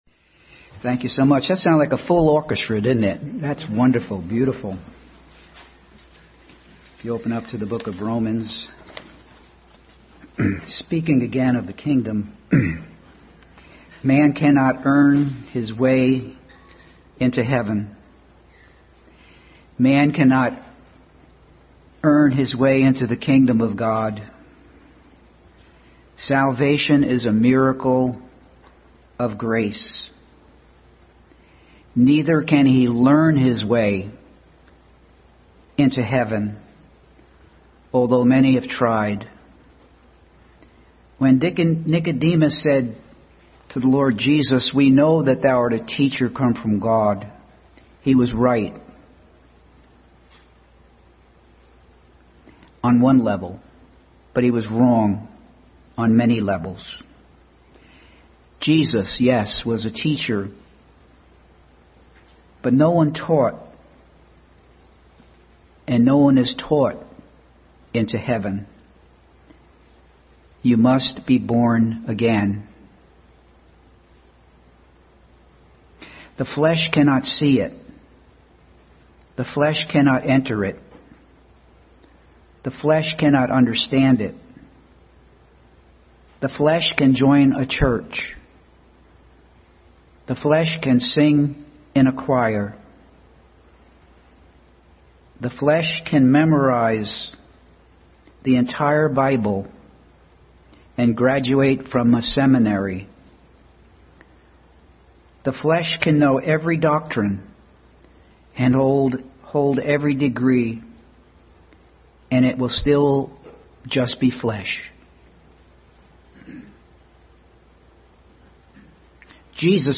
Exodus 10:3 Service Type: Family Bible Hour Continued study in the Book of Romans.